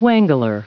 Prononciation du mot wangler en anglais (fichier audio)
Prononciation du mot : wangler